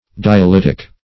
Dialytic - definition of Dialytic - synonyms, pronunciation, spelling from Free Dictionary
Dialytic \Di`a*lyt"ic\, a. [Gr.